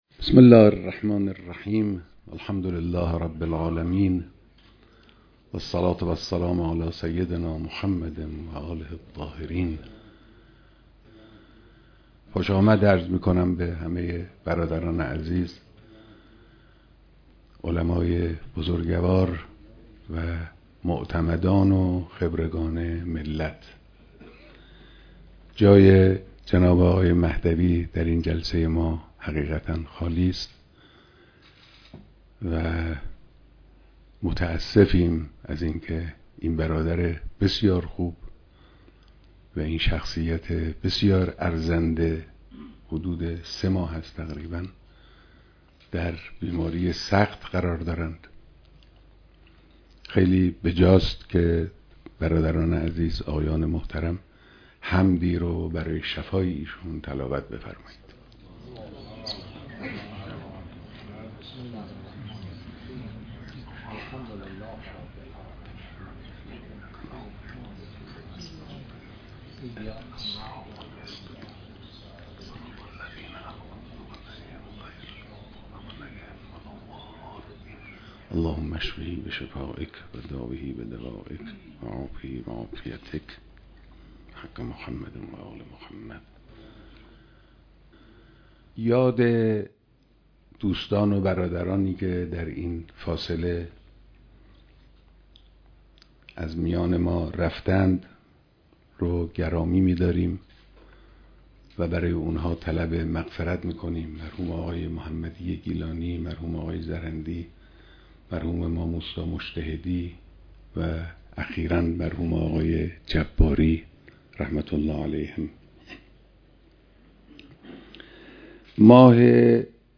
بیانات در دیدار اعضای مجلس خبرگان رهبری